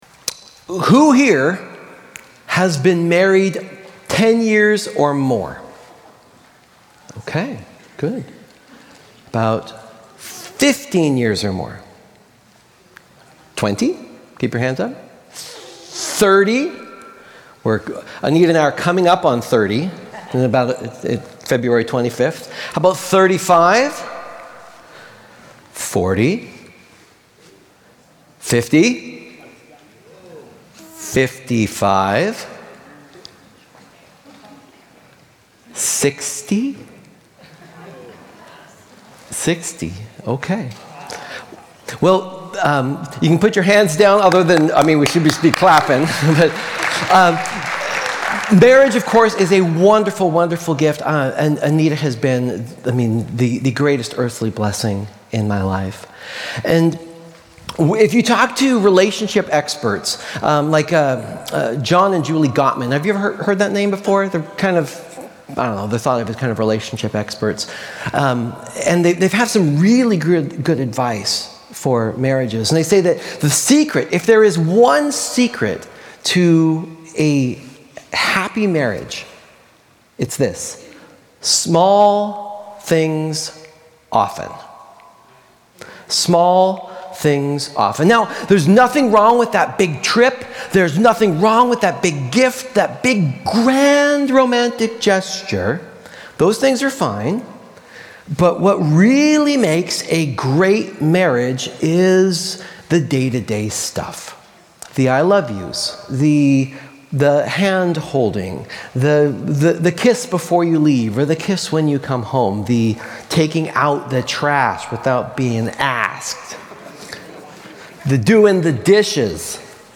Sermons and other audio content from Columbia Grove Covenant Church of East Wenatchee Washington.